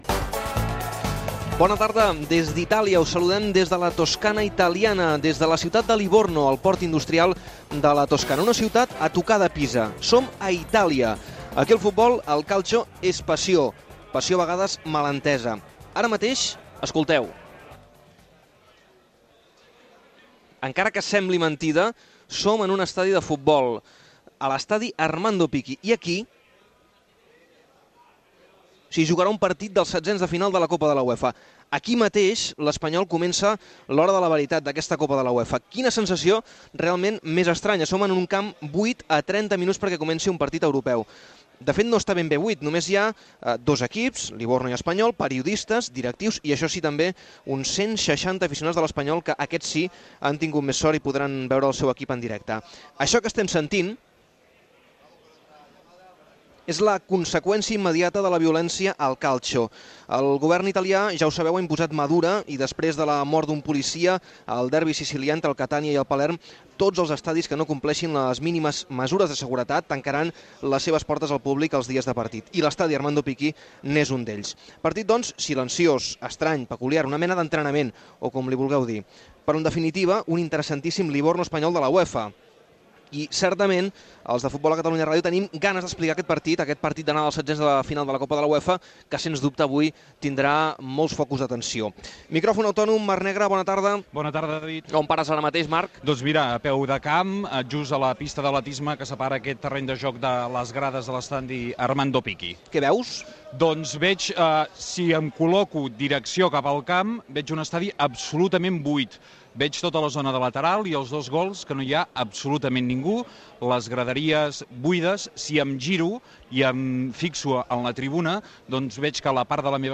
Inici de la transmissió del partit de futbol masculí Livorno - R.C.D.Espanyol, dels setzens de final de l'Europa League, que es juga a porta tancada, sense públic
Esportiu